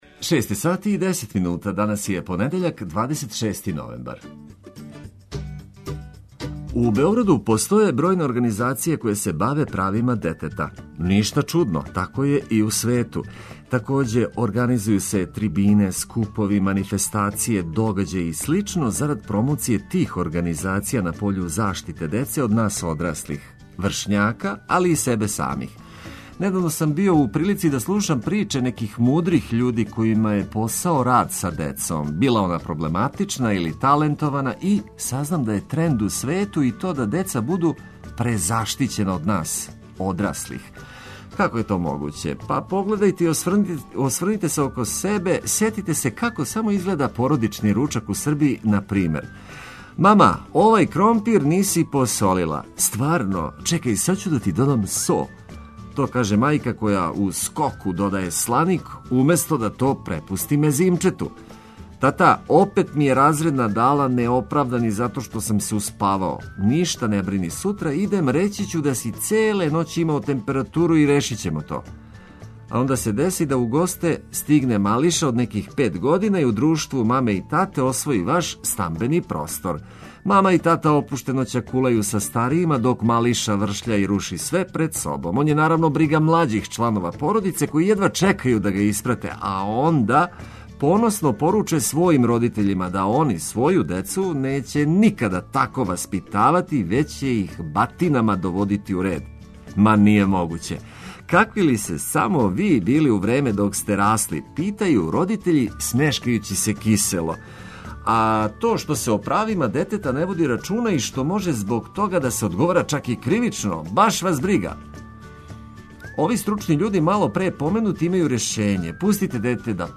Почињемо у шест, ведро и уз блиставе хитове за буђење.
Ту су и редовне рубрике и обиље добре музике.